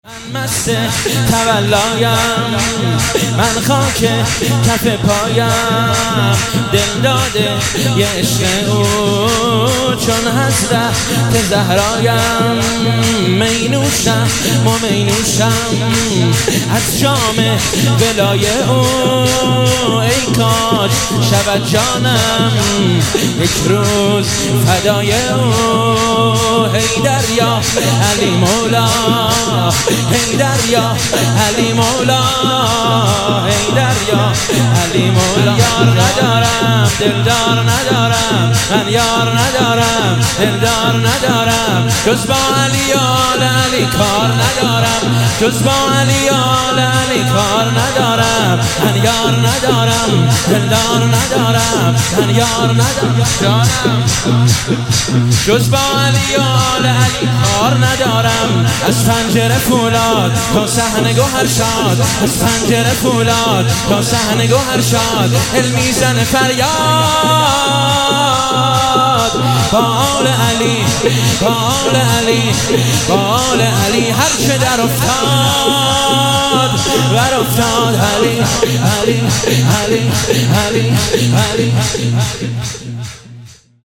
ولادت حضرت زینب (س)